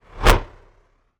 bullet_flyby_deep_03.wav